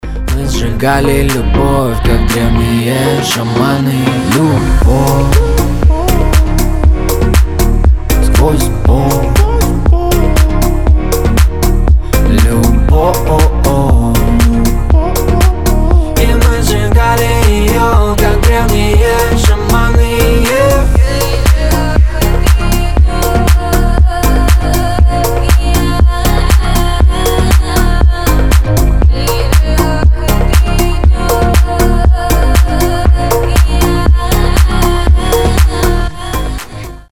• Качество: 320, Stereo
поп
громкие
Хип-хоп
этнические